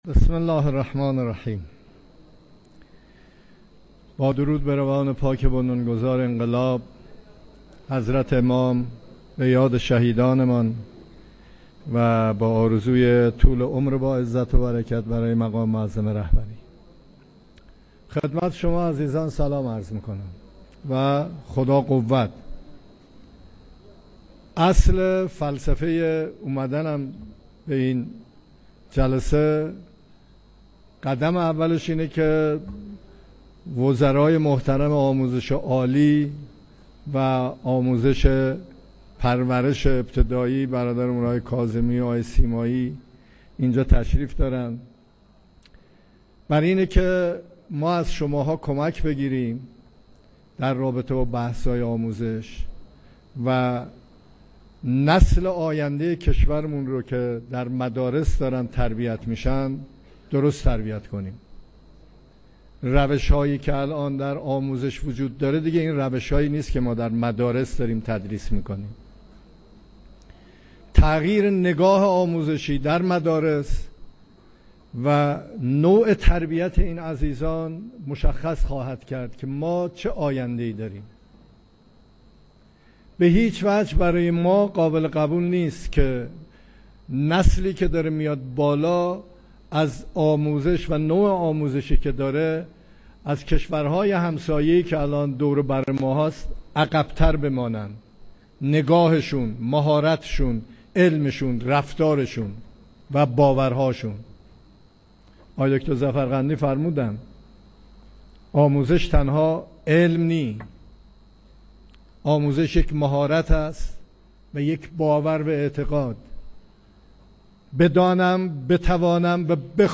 سخنان رئیس جمهور در همایش کشوری آموزش علوم پزشکی
تهران- ایرنا- رئیس‌جمهور امروز چهارشنبه ۳ اردیبهشت ۱۴۰۴ در همایش کشوری آموزش علوم پزشکی، با تاکید بر اینکه این ما هستیم که کشور و آینده را می‌سازیم، گفت: قرار نیست کسی از خارج بیاید و کشور ما را بسازد؛ اگر این باور در ما ایجاد شود هرگز چشم به دیگران نخواهیم دوخت.